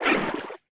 Sfx_creature_babypenguin_hold_equip_under_02.ogg